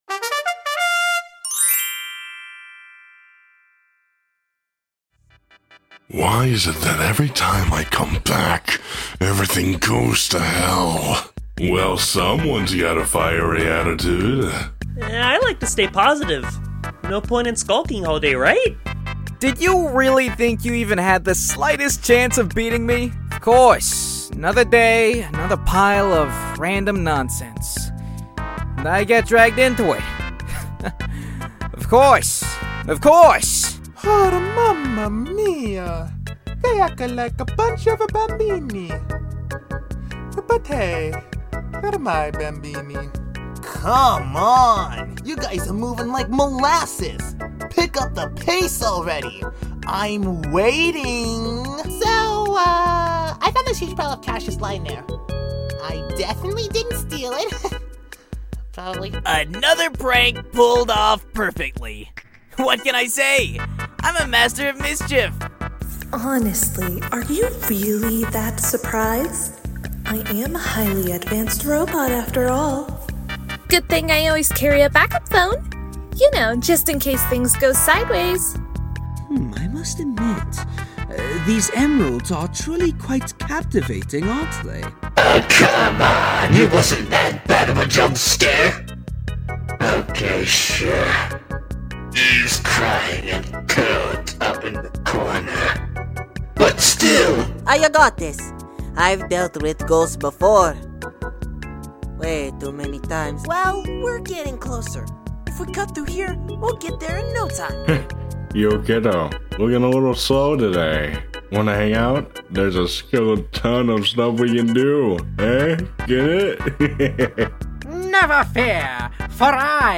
Quantum Quest Voice Actor Showcase I’ve been busy as hell getting voice actors, editing and so much more.